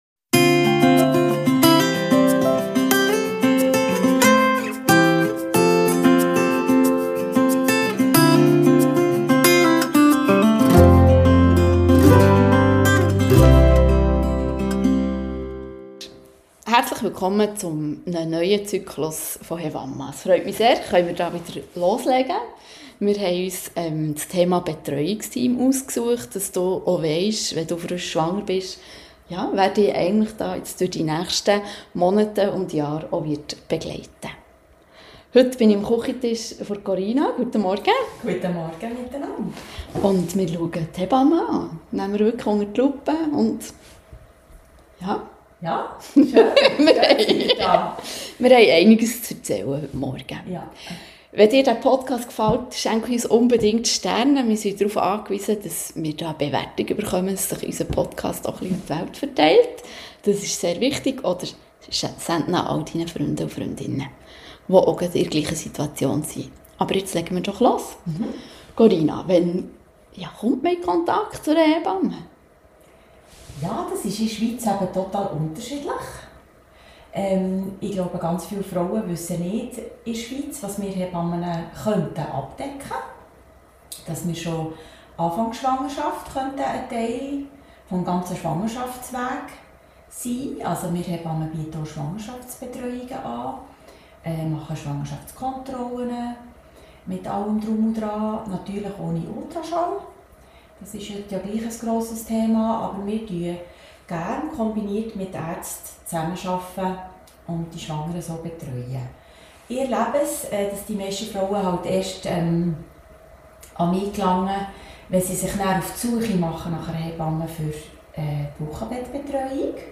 wir zwei Hebammen, erklären klar und praxisnah, wie die Betreuung durch die Hebamme in Schwangerschaft, Geburt und Wochenbett funktioniert.